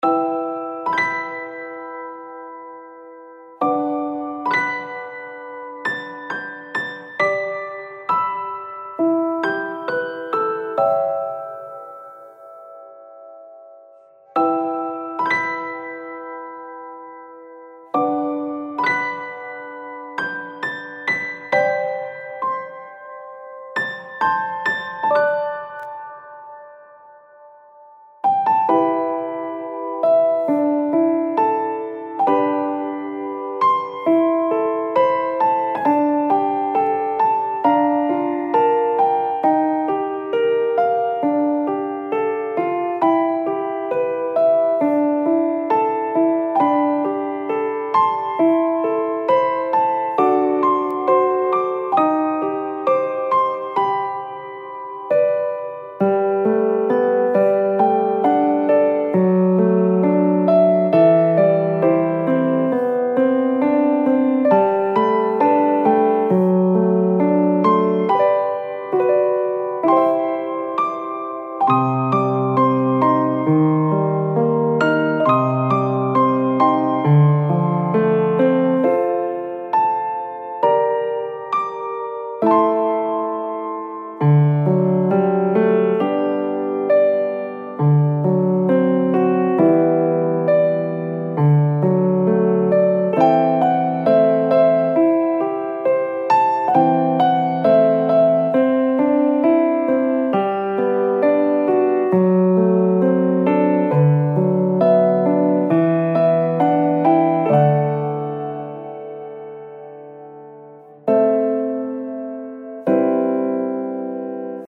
夜の静かな草原をイメージした、ソロピアノBGMです。ピアノの響きはやや暗めながらも温かい雰囲気となっています。